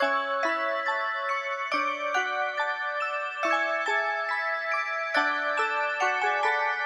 铃铛
Tag: 140 bpm Trap Loops Bells Loops 1.15 MB wav Key : C FL Studio